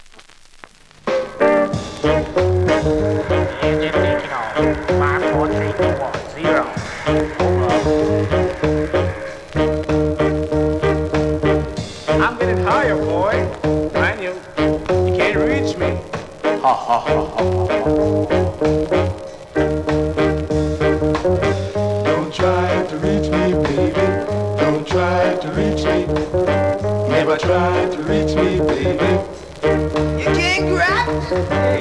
両面プレス起因だと思われますがチリチリ・ノイズあり。